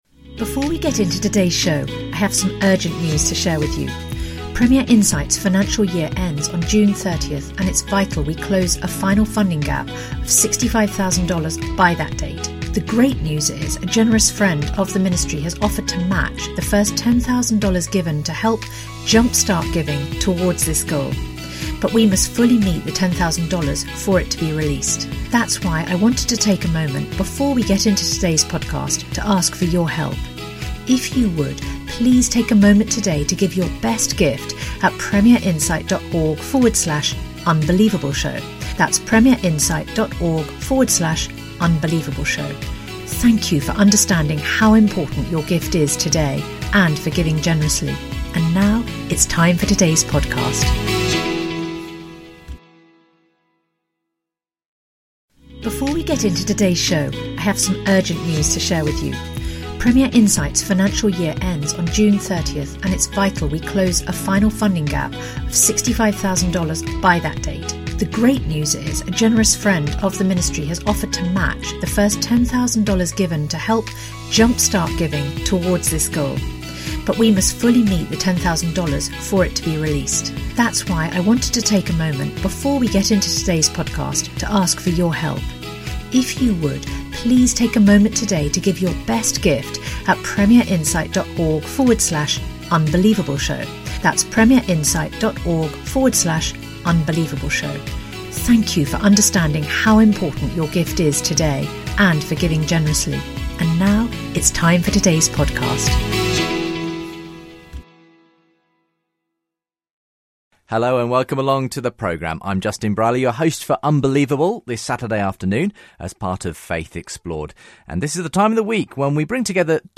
They debate whether Christians should be committed to non-violence, the paradigm of the cross and global conflicts including WWI and the Iraq invasion.